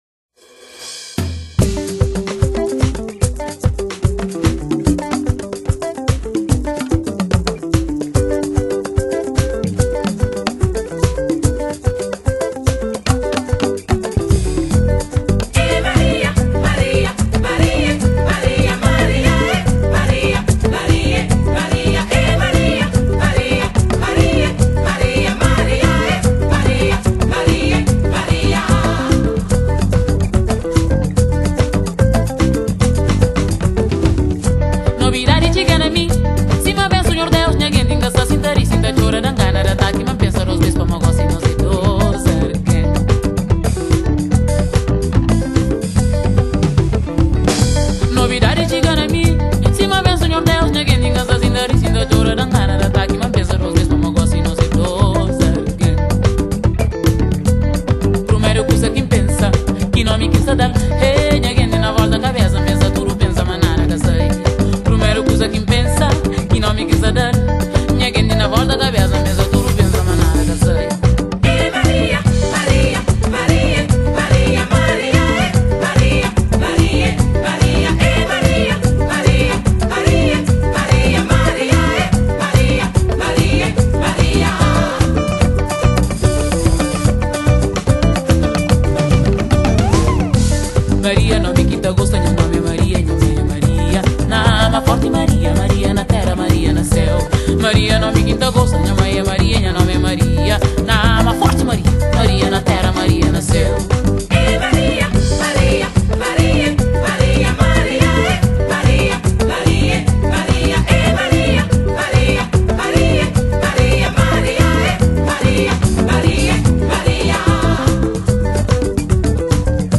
◎專輯風格以傳統的維德角音樂為主，同時廣納爵士、藍調、葡萄牙的fados、探戈音樂等。
◎專輯分別在布魯塞爾、巴黎、那普勒斯以及維德島等地灌錄